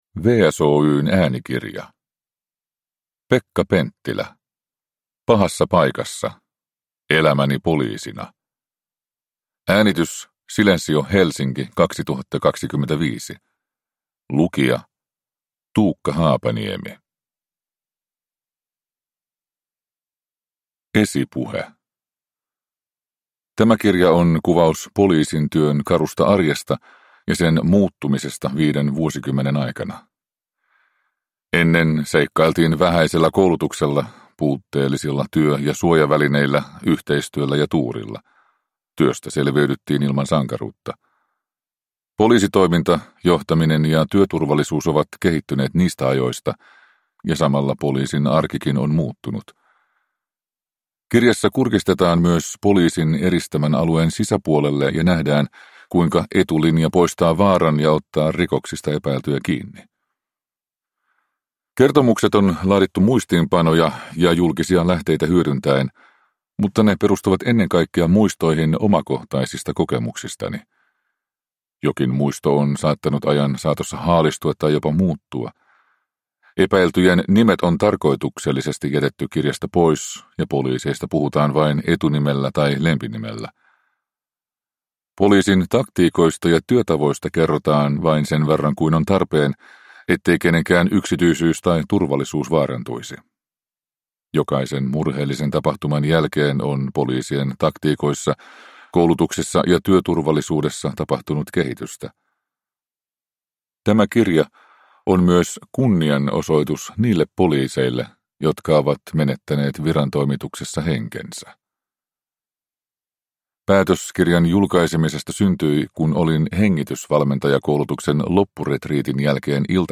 Pahassa paikassa. Elämäni poliisina (ljudbok) av Pekka Penttilä